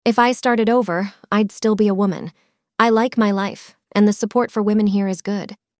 話題性の高いニュースTopic「性別の変更と平等」に関する会話テキストを元に、アメリカ英語の自然な口語を学びます。
友人どうしが時事問題について話し合っています。